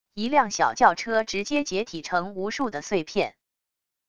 一辆小轿车直接解体成无数的碎片wav音频